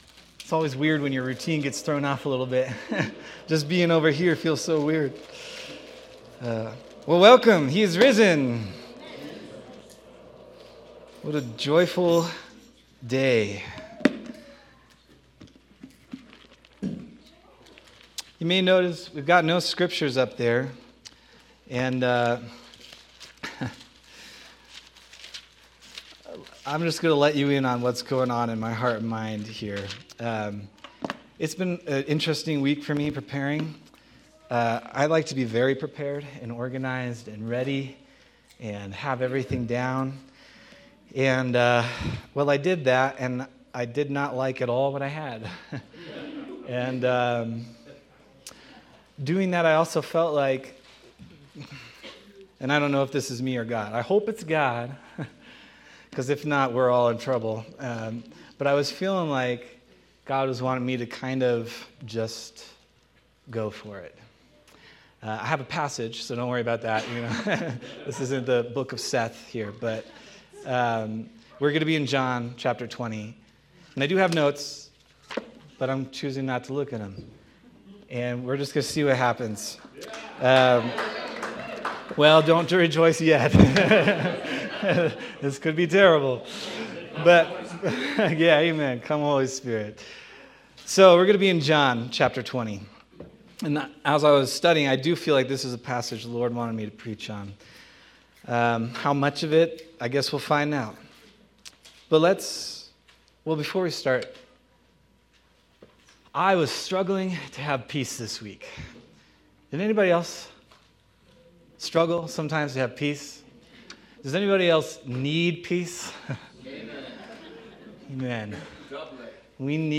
Resurrection Day, 2026 Sermon